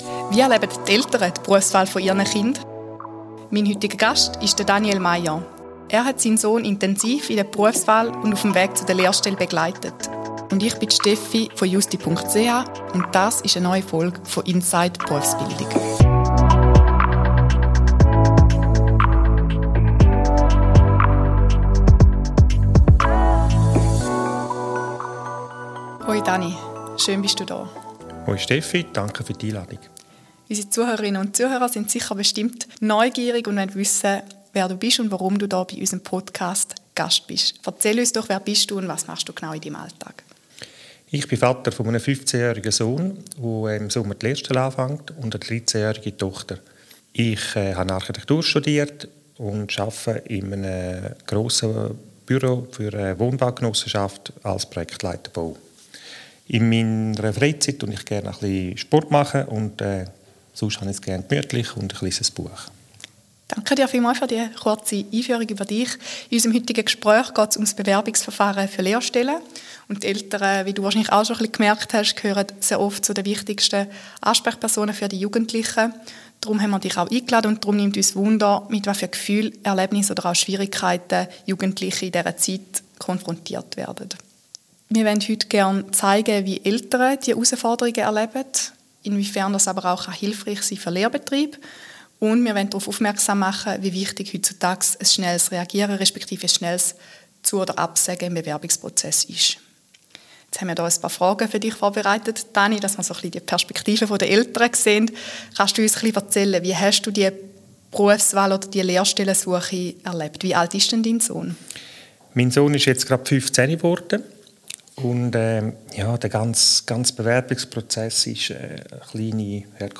spricht in der sechsten Podcastfolge mit einem Papa